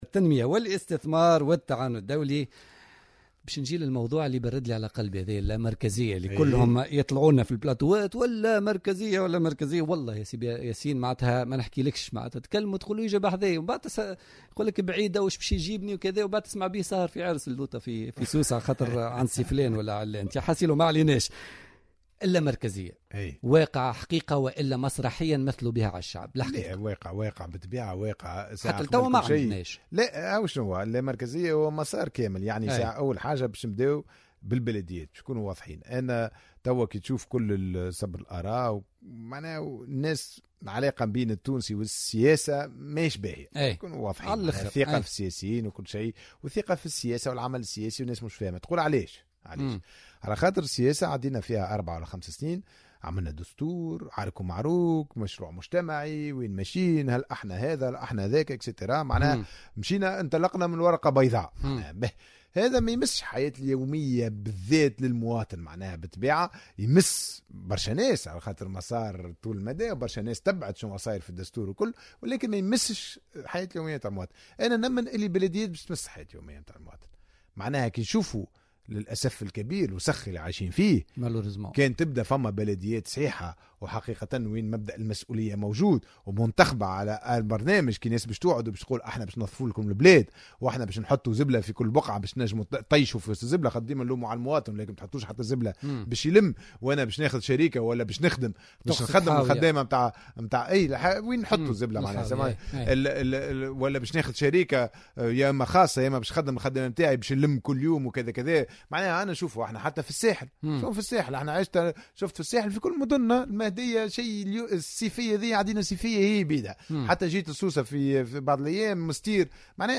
أكد وزير التنمية والاستثمار والتعاون الدولي ياسين ابراهيم ضيف بوليتيكا اليوم الاثنين 14 سبتمبر 2015 أن الانتخابات البلدية سيكون لها دور مهم في تنظيم التسيير اليومي لحياة المواطن وخاصة في حل المشاكل المتعلقة بالبيئة والانتصاب الفوضوي والبناءات العشوائية التي زادت انتشارا خاصة بعد الثورة.